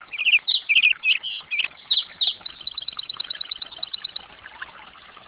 Mountain Birds.wav